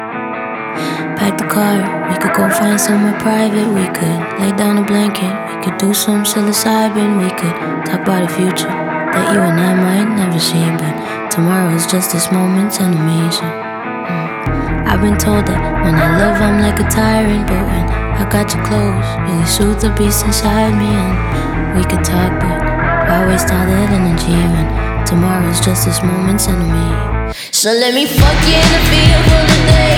R B Soul